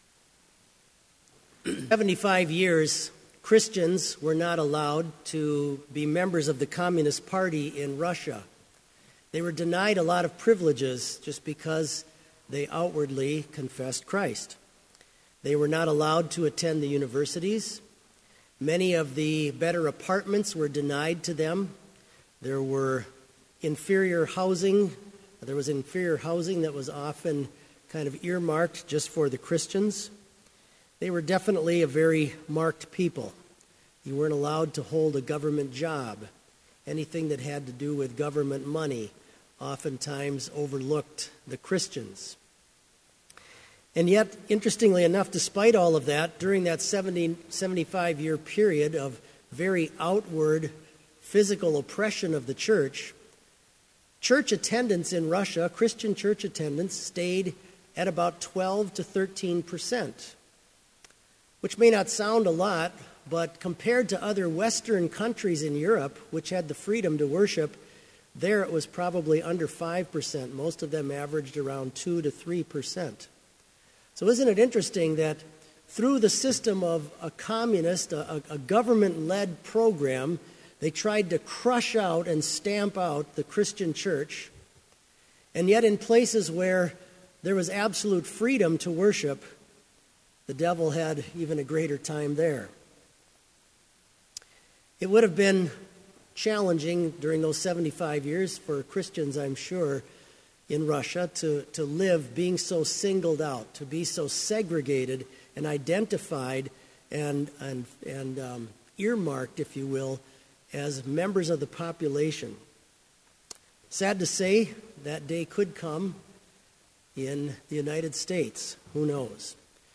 Sermon audio for Evening Vespers - April 20, 2016